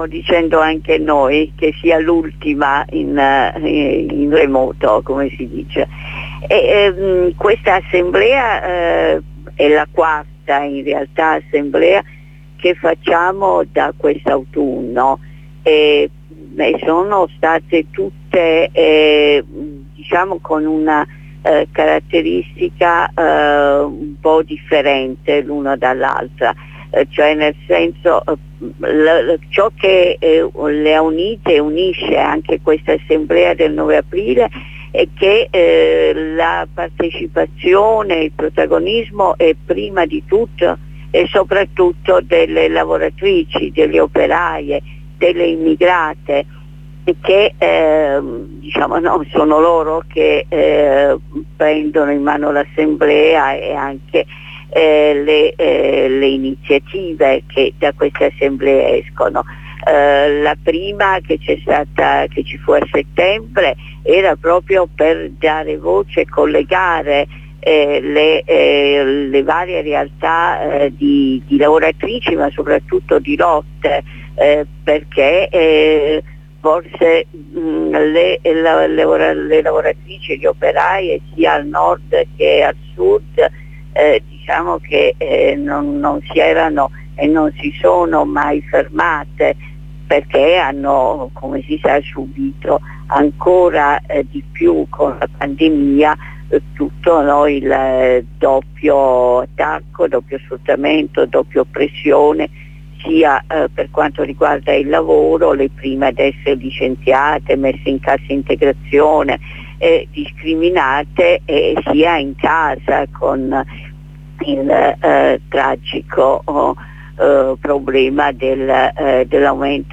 Ne parliamo con una compagna del MFPR.